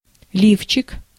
Ääntäminen
Synonyymit бюстгальтер Ääntäminen Tuntematon aksentti: IPA: /ˈlʲift͡ɕɪk/ Haettu sana löytyi näillä lähdekielillä: venäjä Käännös Ääninäyte Substantiivit 1. brassiere US 2. bra Translitterointi: liftšik.